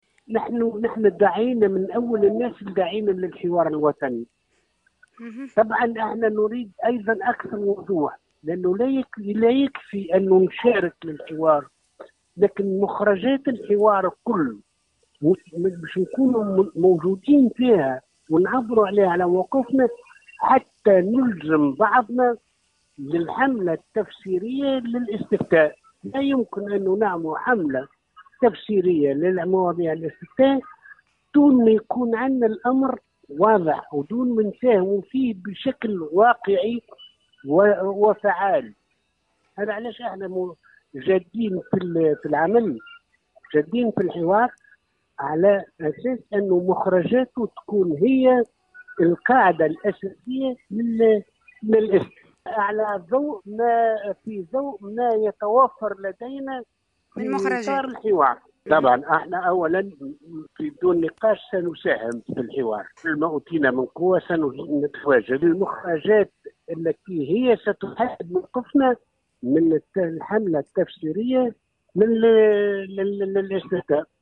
في تصريح لمراسلة الجوهرة اف ام، على هامش اختتام المجلس المركزي للحركة، المنعقد اليوم الجمعة بالحمامات